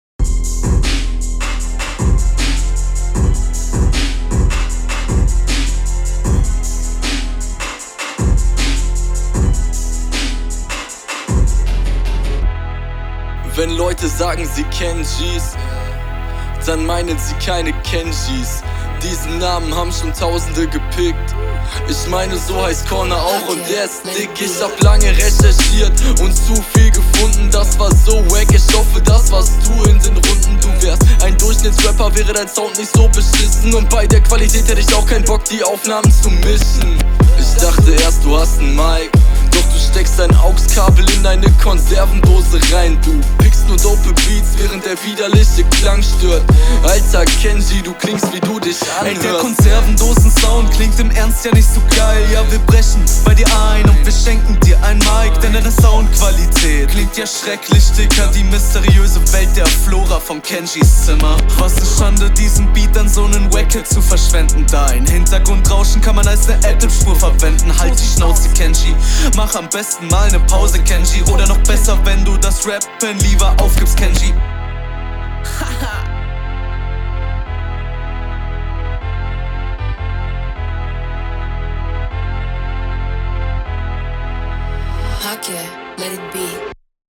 erste Line fand ich nice ich mag sone Wortspiele doch seine Soundqualität is nicht mal …